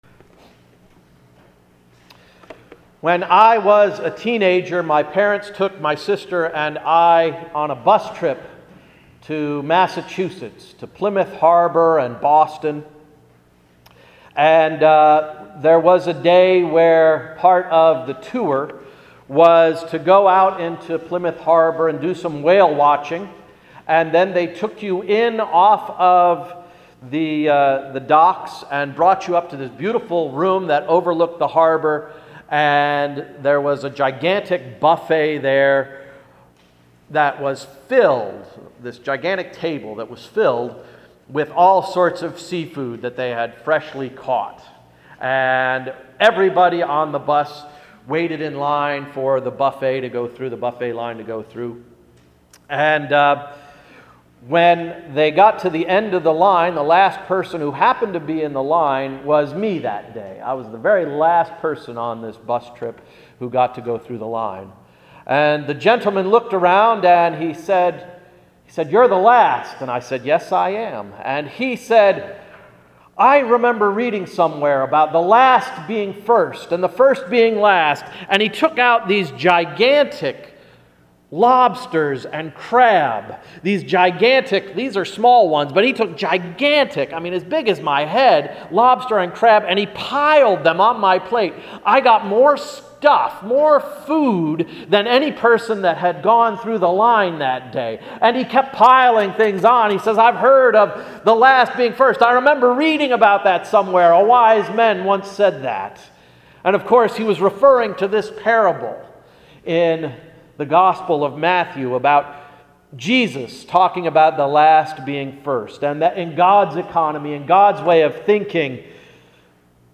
Sermon of September 21, 2014–“Award for Last Place”